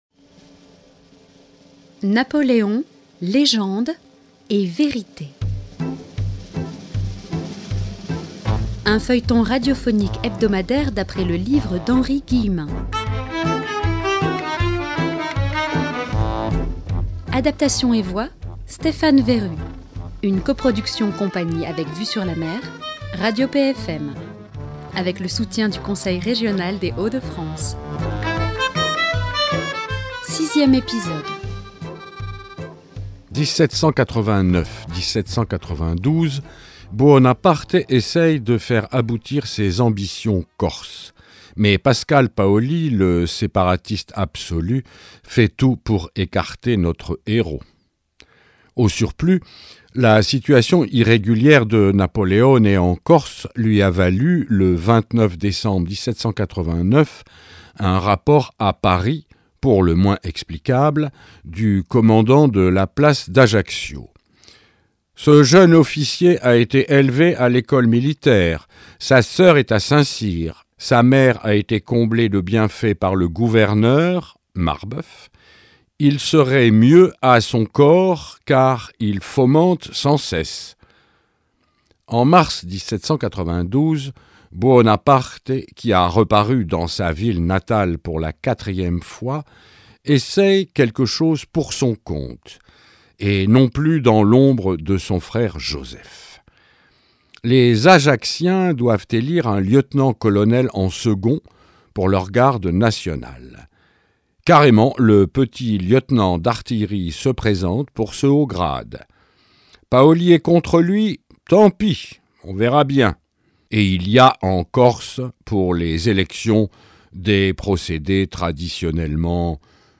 a adapté en feuilleton radiophonique l’ouvrage de l’historien iconoclaste Henri Guillemin